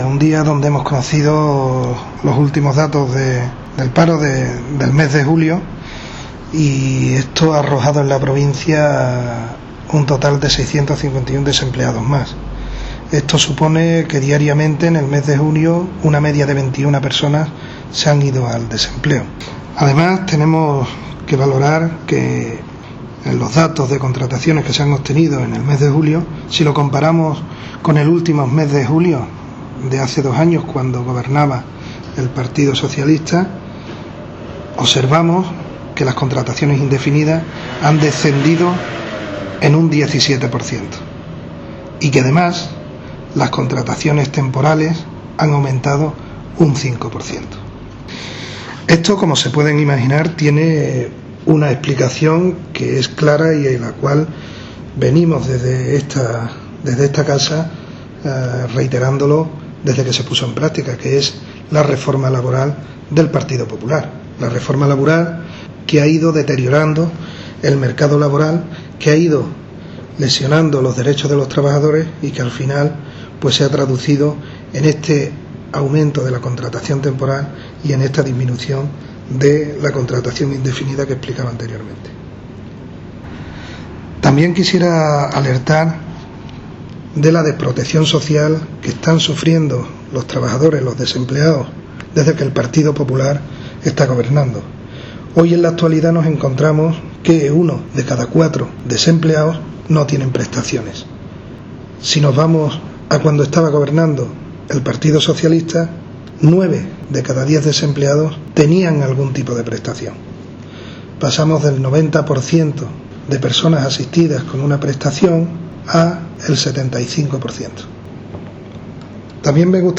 Valoración delegado Economía paro julio.mp3